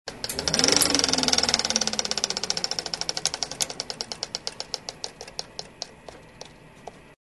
На этой странице собраны звуки кинопроектора в высоком качестве – от мягкого гула до характерных щелчков пленки.
Звук запуска ручного 35-мм проектора